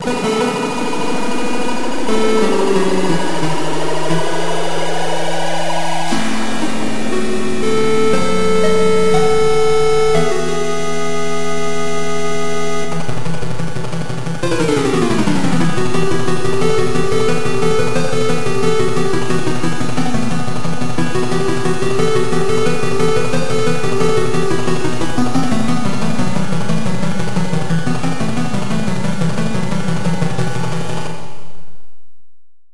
Reverb version